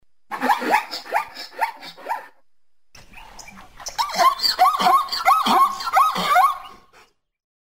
ZEBRA
Zebra.mp3